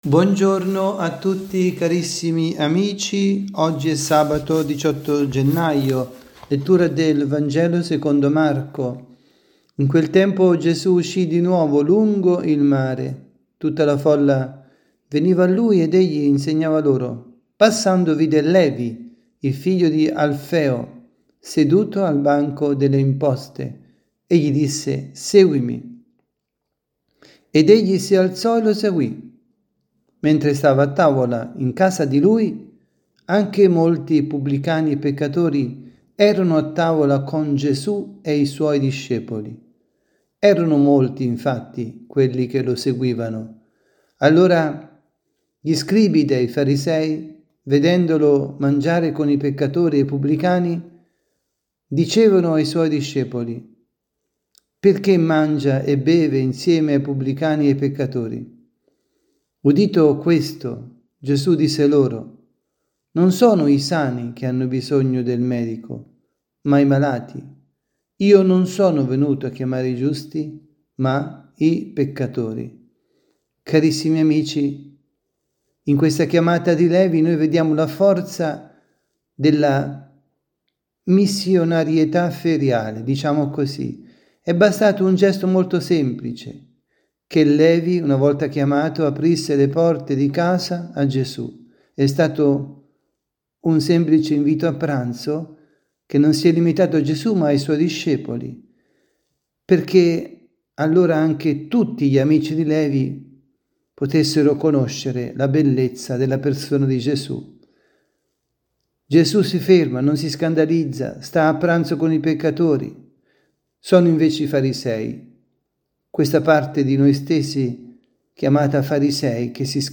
Catechesi
dalla Basilica di San Nicola – Tolentino